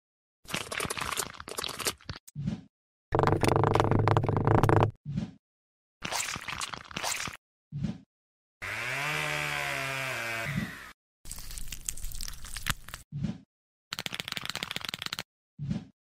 ⚽ Kaká ASMR, Brazilian Football sound effects free download
Icon Whispers!